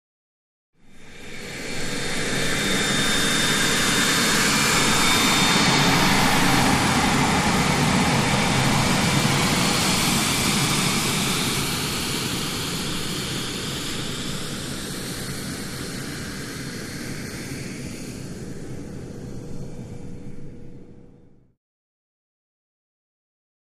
Marchetti: Slow By; Slow Taxi By, High-pitched Engine At Low Rpm. Close To Medium Perspective. Jet.